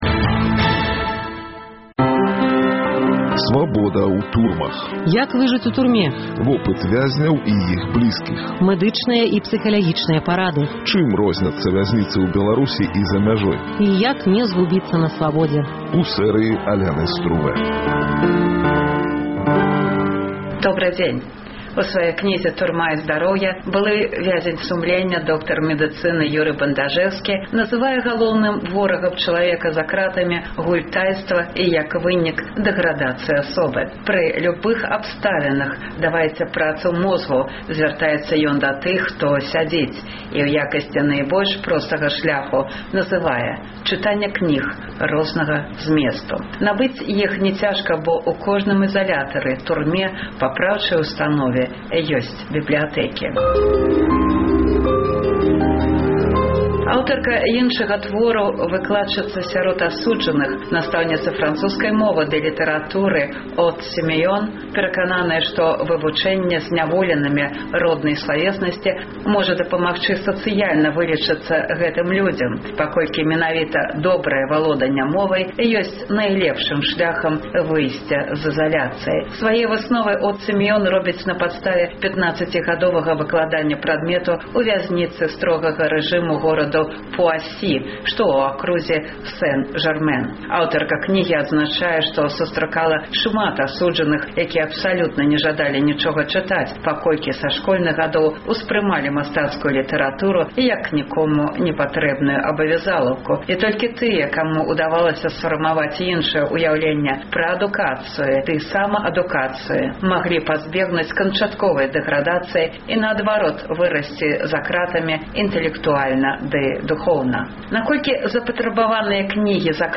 Наколькі ўвогуле запатрабаваныя за кратамі кнігі? На гэтыя ды іншыя пытаньні ў чарговым выпуску "Свабоды ў турмах" адказваюць нядаўнія асуджаныя, а таксама сваякі тых, хто яшчэ сядзіць.